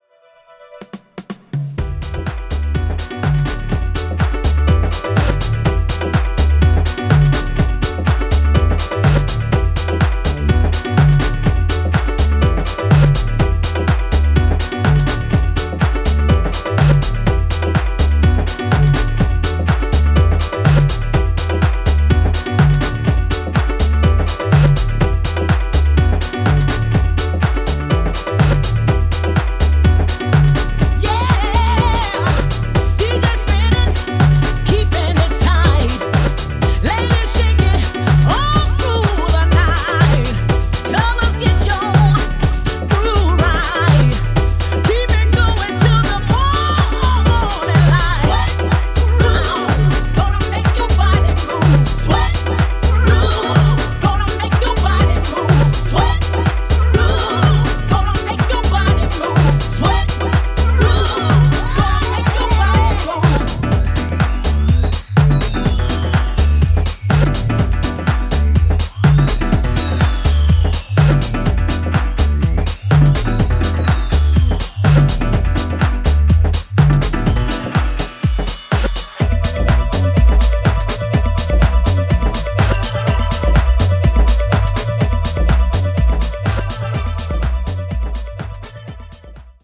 CLUB ELECTRONIQUE
ジャンル(スタイル) HOUSE / SOULFUL HOUSE / DISCO HOUSE